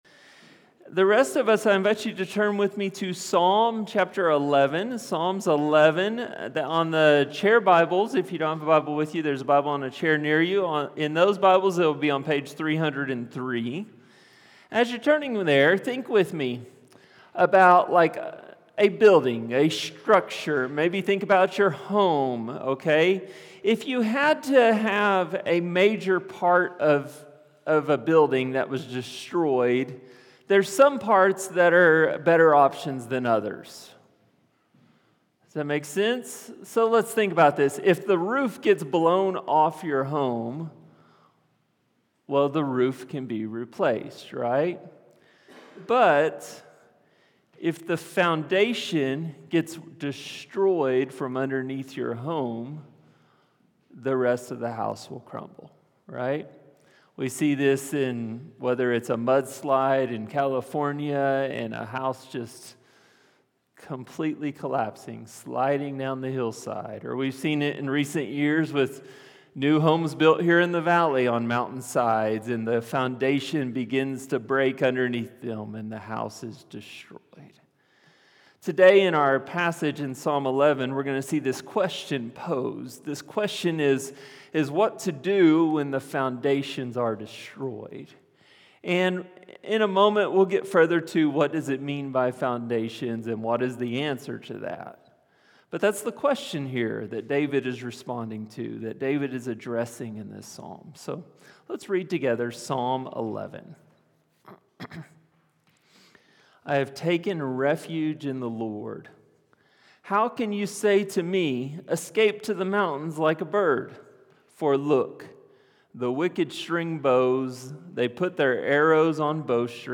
Sermons | HopeValley Church // West Jordan, UT